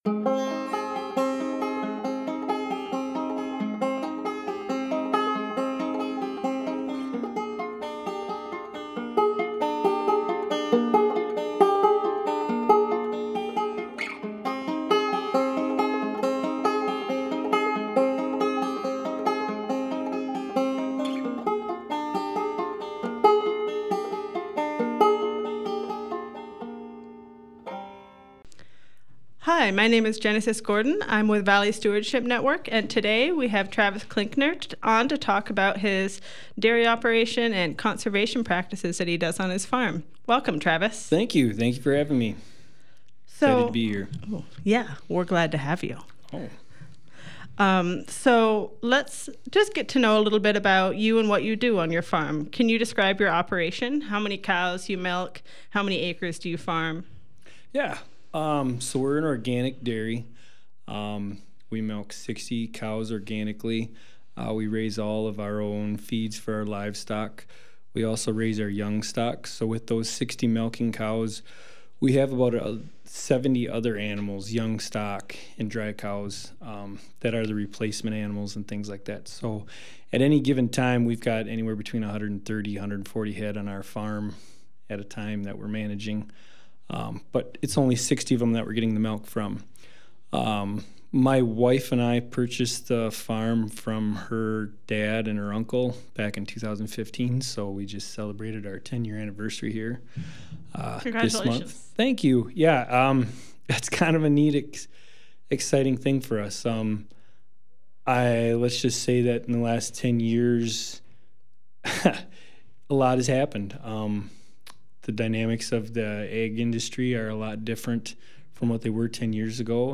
A Conversation with local farmer